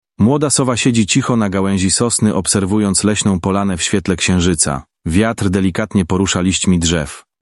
ElevenLabs_Polishmale.mp3